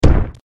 tt_s_ara_cmg_cogStomp.ogg